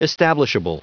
Prononciation du mot establishable en anglais (fichier audio)
Prononciation du mot : establishable